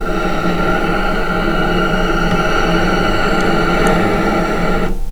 healing-soundscapes/Sound Banks/HSS_OP_Pack/Strings/cello/sul-ponticello/vc_sp-F#6-pp.AIF at b3491bb4d8ce6d21e289ff40adc3c6f654cc89a0
vc_sp-F#6-pp.AIF